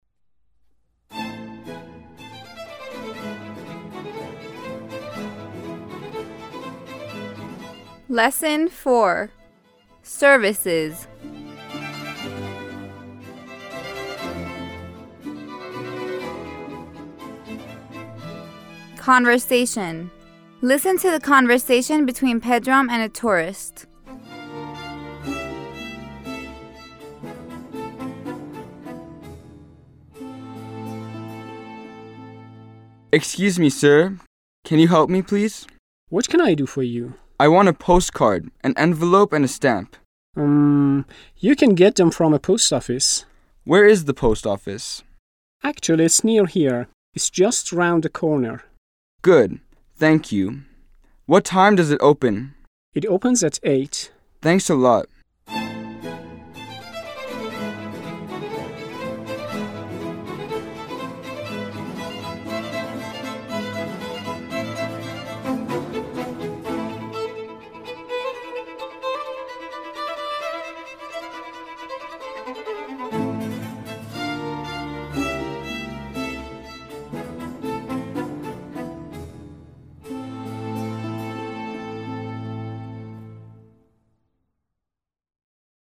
9-L4-Conversation
9-L4-Conversation.mp3